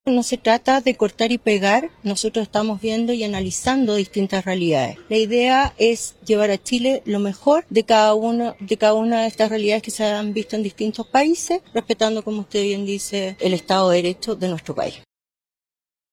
En ese sentido, al ser consultada por las críticas al modelo “Bukele”, la futura ministra de Seguridad sostuvo que no intentarán replicar a raja tabla las medidas, sino que buscarán traer lo mejor del modelo a Chile.